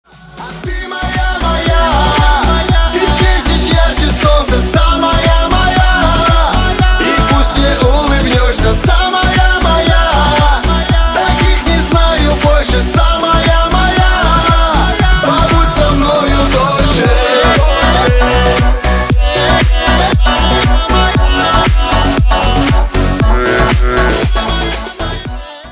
мужской вокал
восточные мотивы